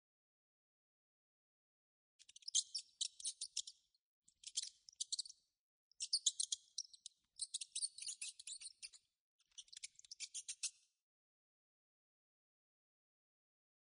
sfx_老鼠叫.wav